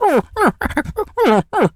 pgs/Assets/Audio/Animal_Impersonations/dog_whimper_cry_04.wav at master
dog_whimper_cry_04.wav